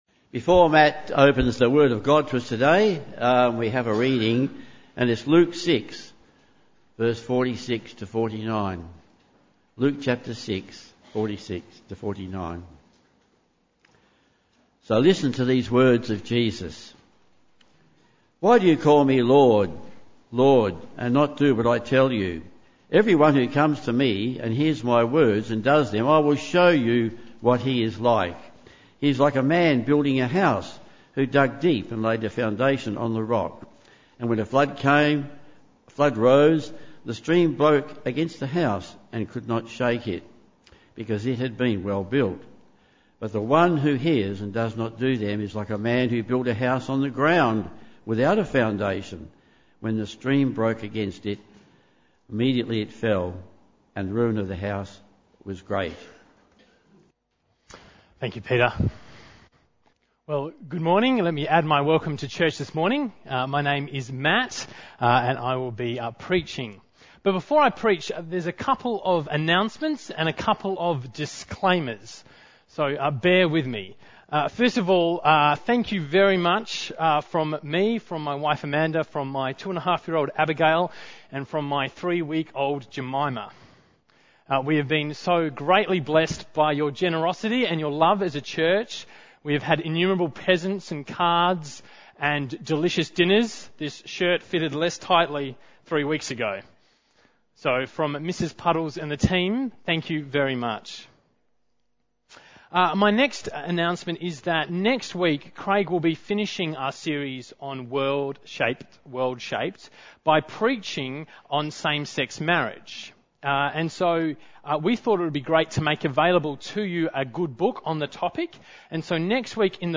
Passage: Luke 6:46-49 Service Type: Morning Service Bible Text